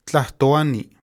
Tlahtoāni[1] (Classical Nahuatl: tlahtoāni pronounced [t͡ɬaʔtoˈaːniˀ]